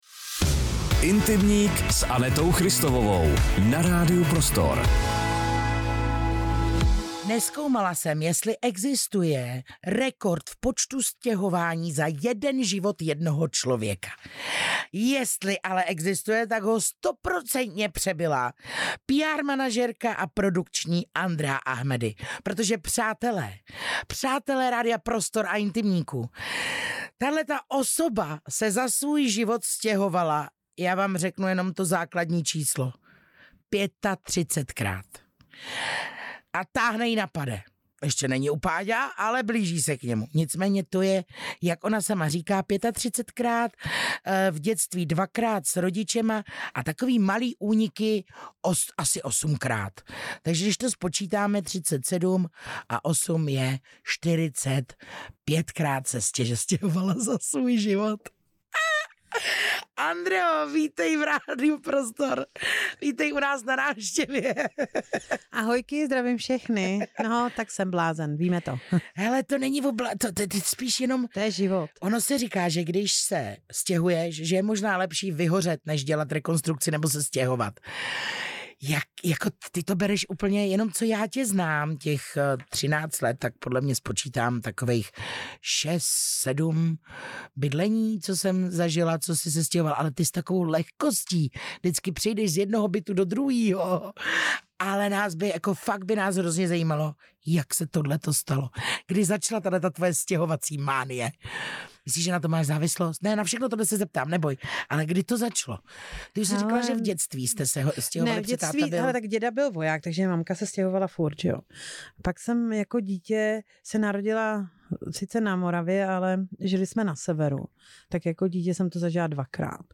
Poslechněte si celý rozhovor a zjistěte, proč někdo dokáže najít domov i v jedné hokejové tašce.